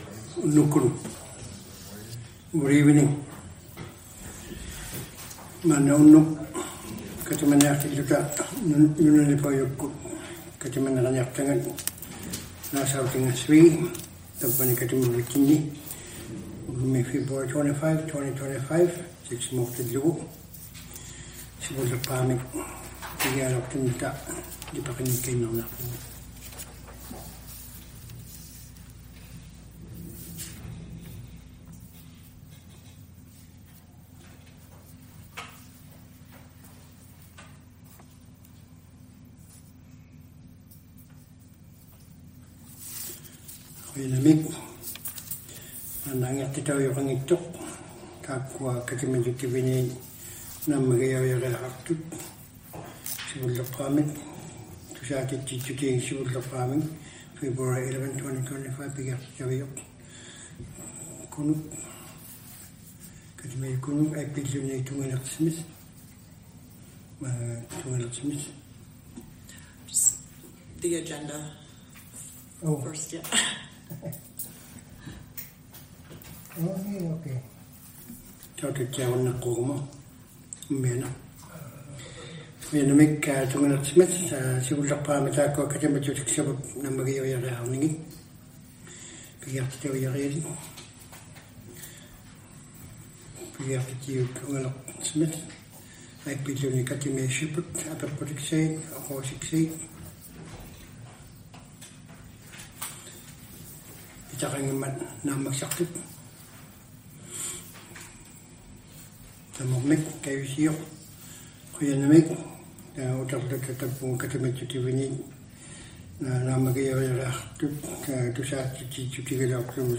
Due to technical issues with our AV system, the audio was recorded on a different device, which unfortunately does not allow us to separate the Inuktitut and English language tracks.